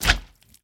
MinecraftConsoles / Minecraft.Client / Windows64Media / Sound / Minecraft / mob / slime / big4.ogg